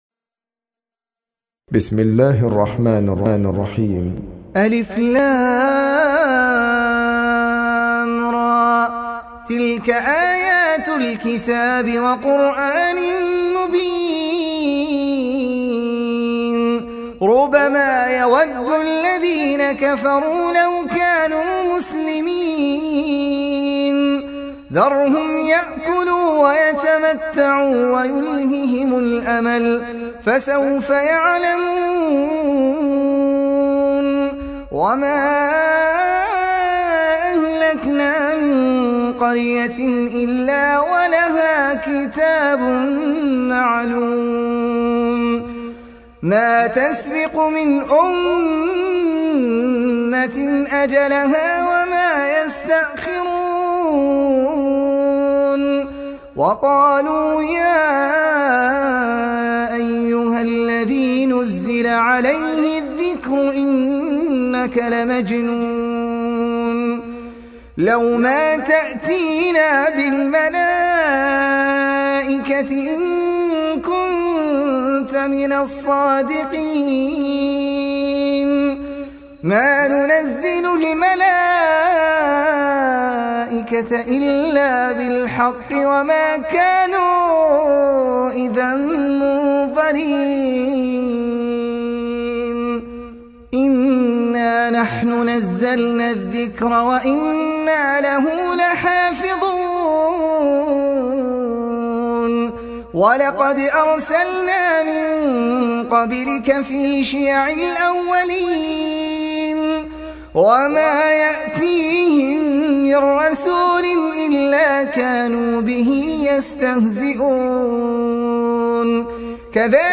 سورة الحجر | القارئ أحمد العجمي